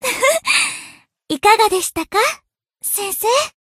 贡献 ） 分类:蔚蓝档案语音 协议:Copyright 您不可以覆盖此文件。
BA_V_Hanako_Battle_Victory_2.ogg